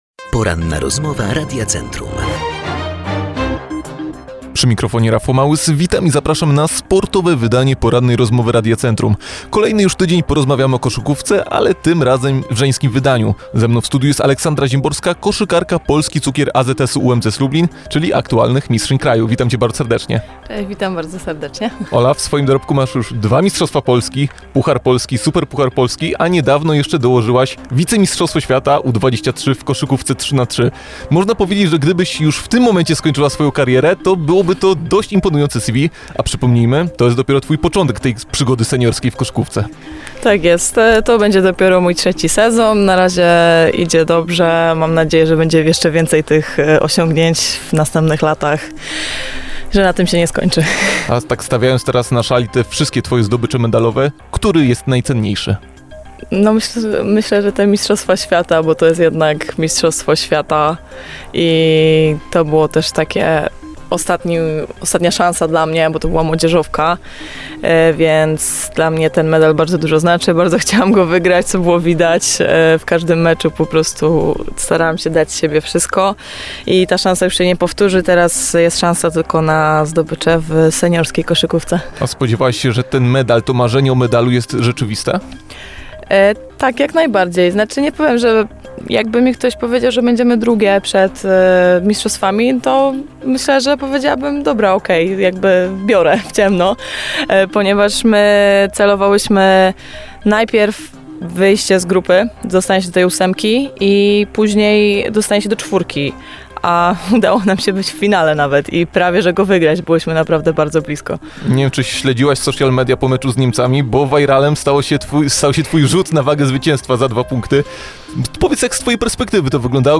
Cała rozmowa dostępna poniżej.
ROZMOWA.mp3